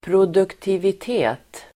Ladda ner uttalet
produktivitet substantiv, productivity Uttal: [produktivit'e:t] Böjningar: produktiviteten Definition: förhållandet mellan insats och resultat vid industriproduktion Exempel: ökad produktivitet (increased productivity)